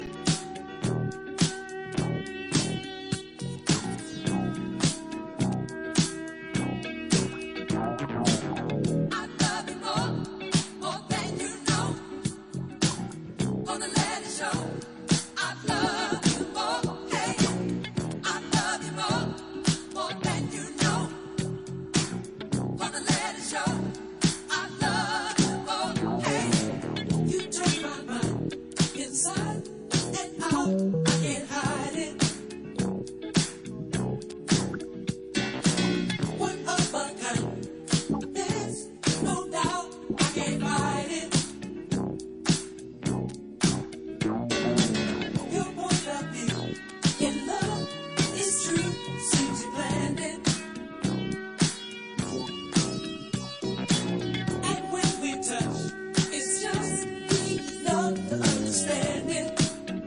Absolute 80’s bombs